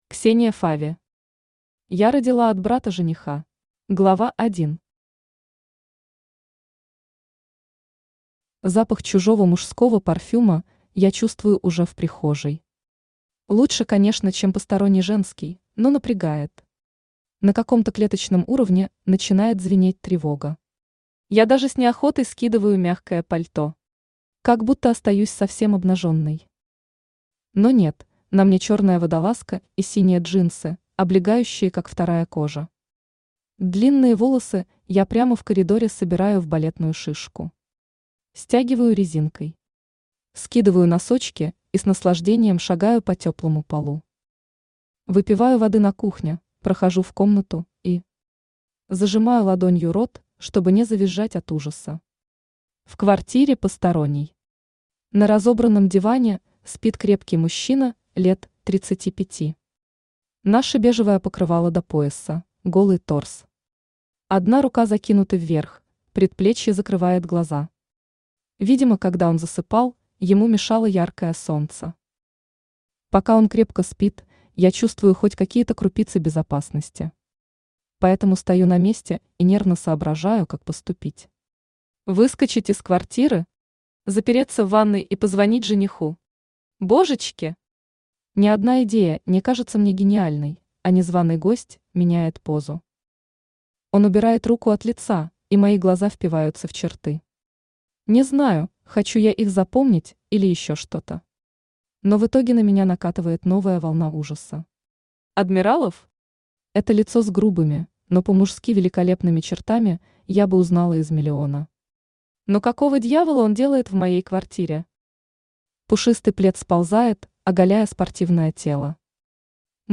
Аудиокнига Я родила от брата жениха | Библиотека аудиокниг
Aудиокнига Я родила от брата жениха Автор Ксения Фави Читает аудиокнигу Авточтец ЛитРес.